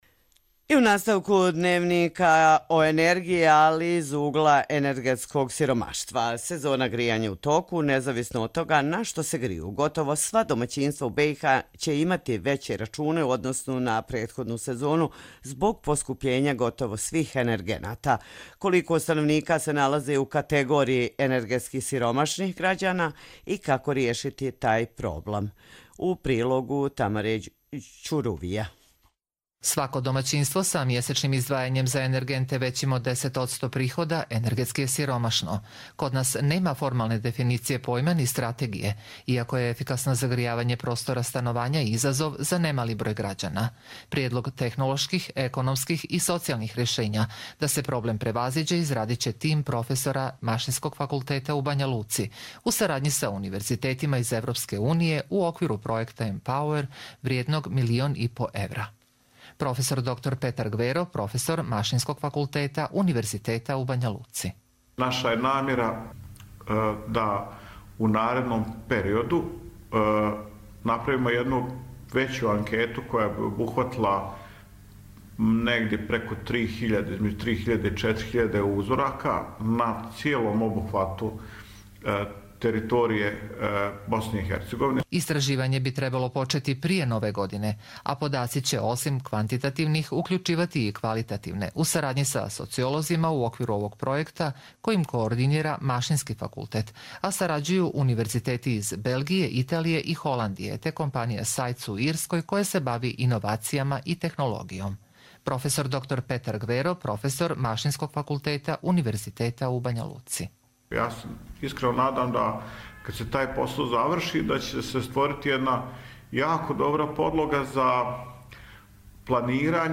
Радио репортажа